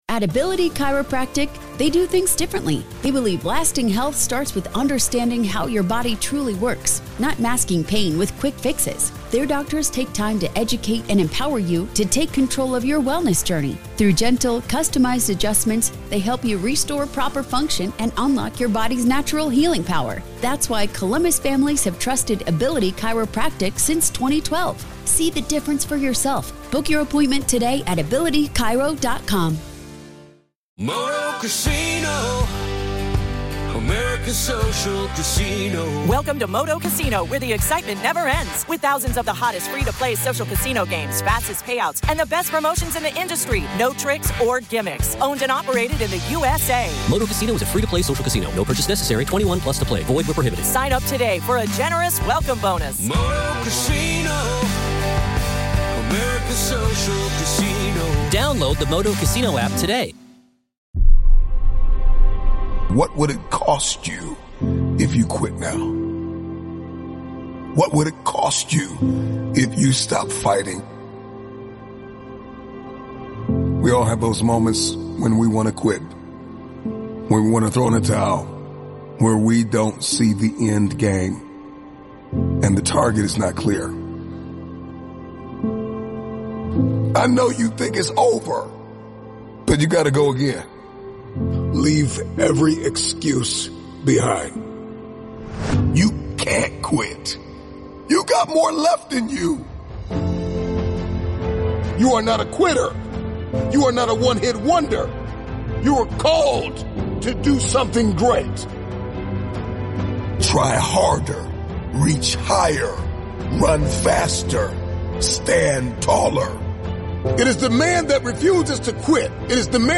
Don't give up until you win. One of the Motivational Speech